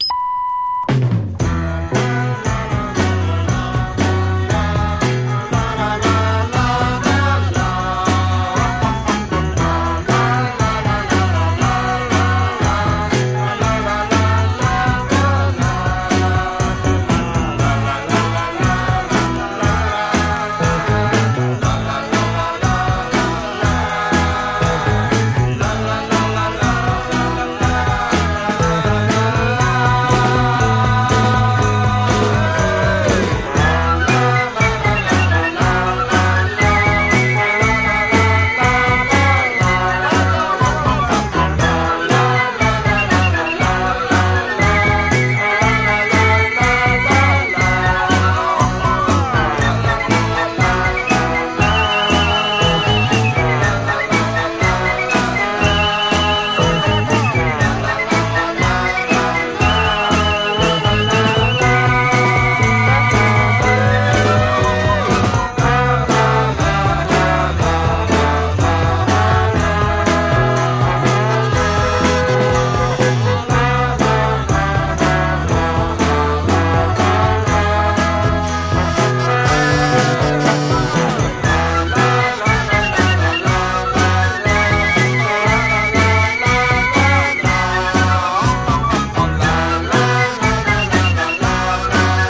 マジカルなカンタベリー・ロック/屈折サイケデリック・ポップ最高峰！
牧歌的な屈折ポップの魅力が詰まった